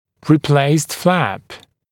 [rɪ’pleɪst flæp][ри’плэйст флэп ]перемещенный лоскут